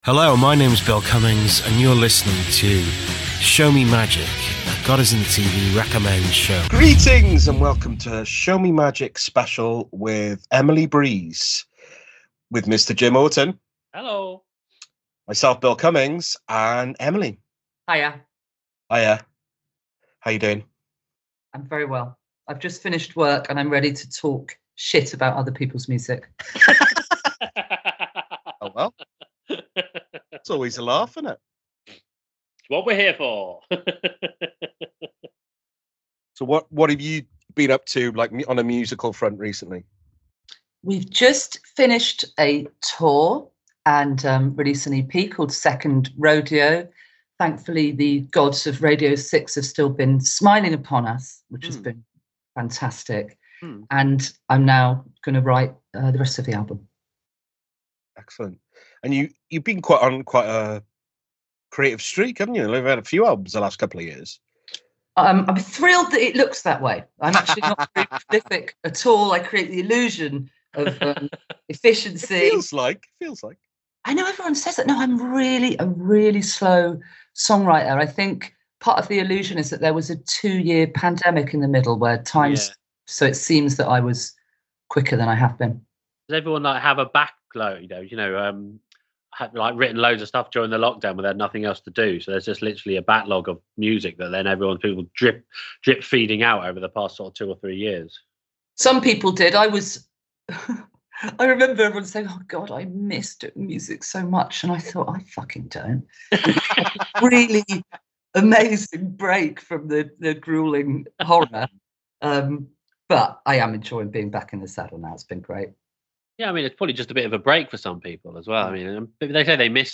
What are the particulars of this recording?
Songs are clipped out for copyright.